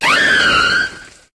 Cri de Majaspic dans Pokémon HOME.